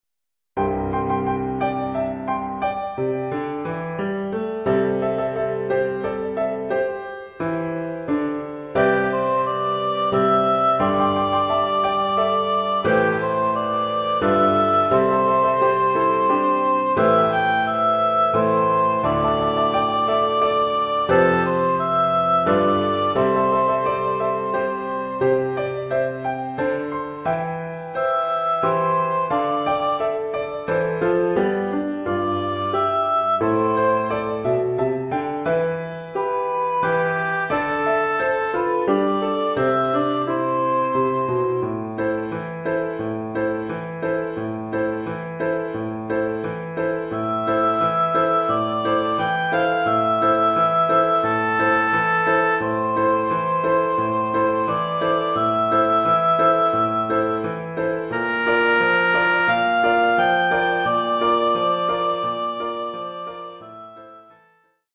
Oeuvre pour hautbois et piano.
Niveau : débutant.